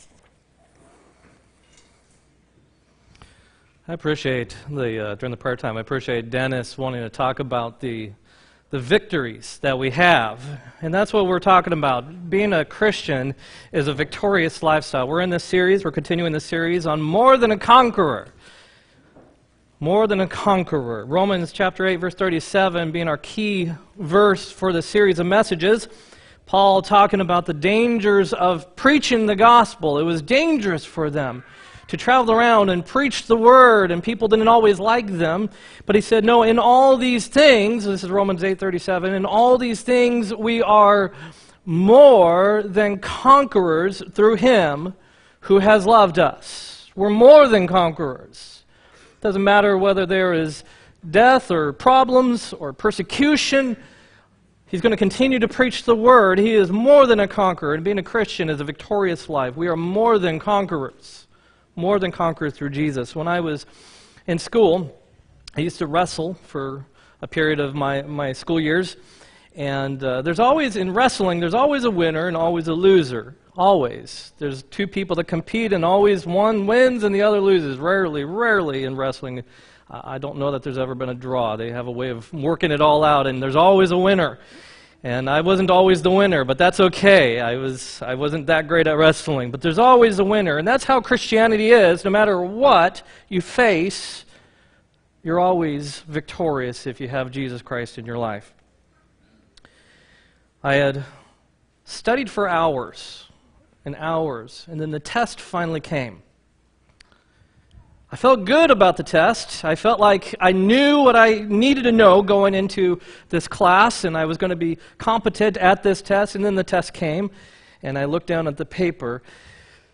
4-21-18 sermon